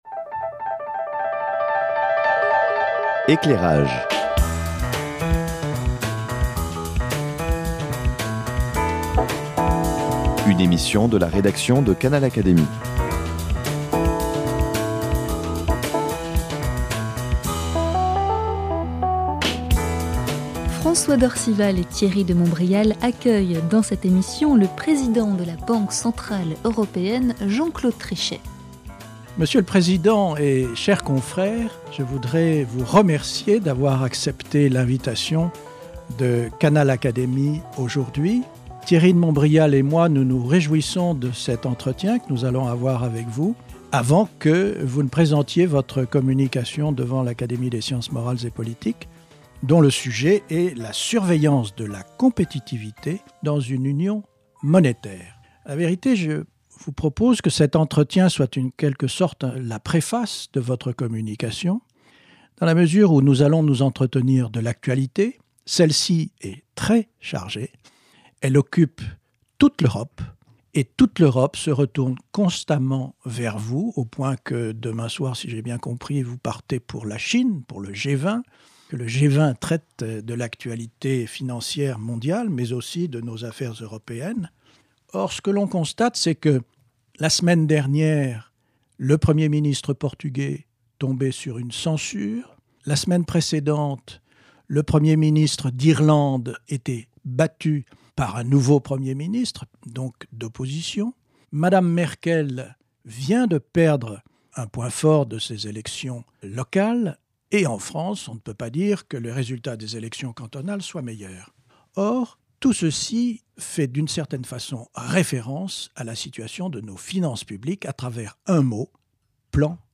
Jean-Claude Trichet, président de la Banque centrale européenne s’est entretenu avec ses deux confrères de l’Académie des sciences morales et politiques : Thierry de Montbrial et François d’Orcival, au micro de Canal Académie. Redresser les finances publiques des pays de la zone euro avec des plans de rigueur suscite des mouvements d’opinion hostiles... et pourtant, aucune reprise de la croissance n’est possible sans finances saines.